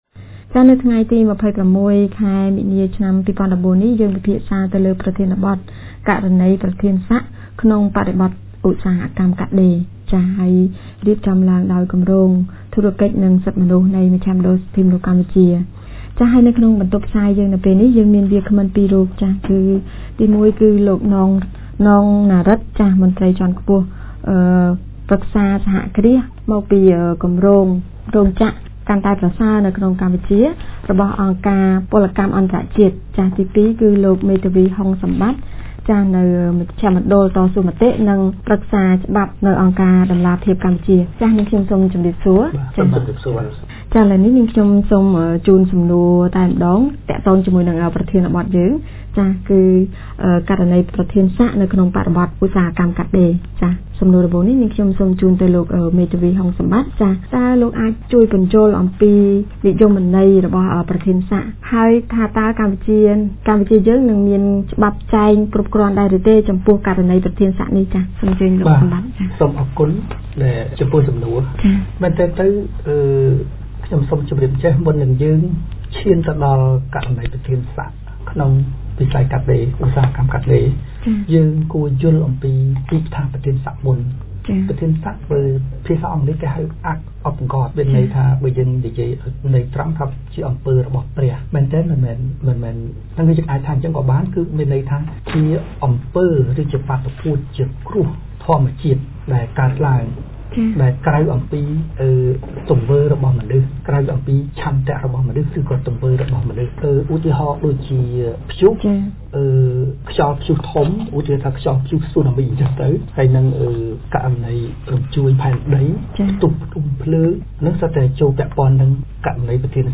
On 26 March 204, BHR Project conducted a radio talk show discussing the situation of Acts of God in the context of garment industry in Cambodia. Our guest speakers came from Transparency International Cambodia and ILO-BFC giving an overview of the Acts of God and responsibility of person in charge of the garment factory.